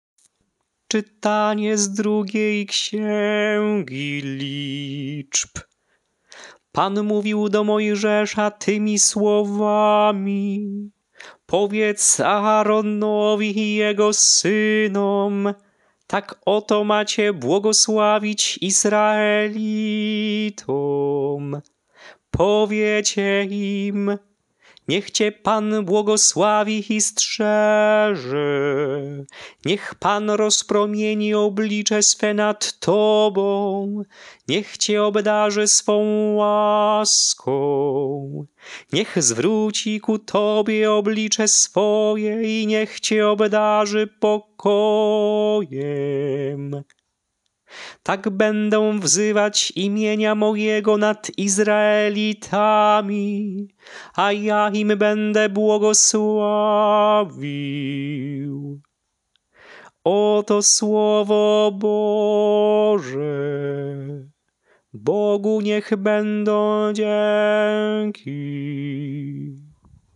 Śpiewane lekcje mszalne - Uroczystość Świętej Bożej Rodzicielki Maryi.
Melodie lekcji mszalnych przed Ewangelią na Uroczystość Świętej Bożej Rodzicielki Maryi:
Swietej-Bozej-Rodzicielki-Maryi-ton-proroctwa.mp3